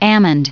second of the given pronunciations in the Merriam Webster online dictionary at Almond Definition & Meaning - Merriam-Webster, almost exactly.